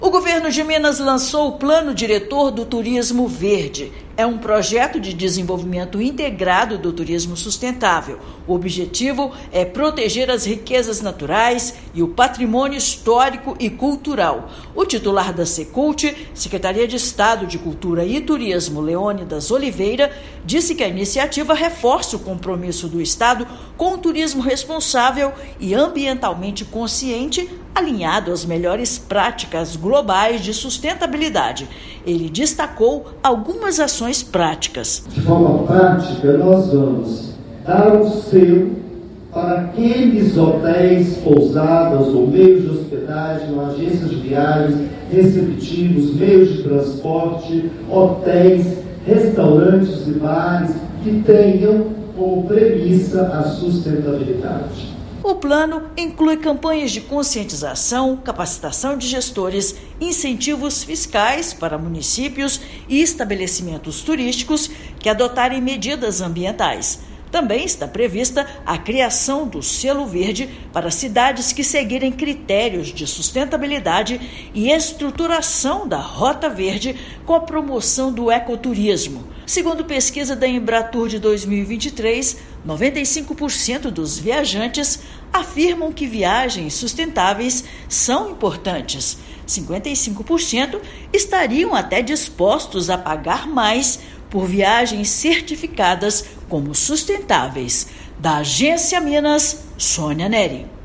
Programa envolve ações como campanhas educativas, incentivos fiscais, capacitação de gestores e estruturação da Rota Verde. Ouça matéria de rádio.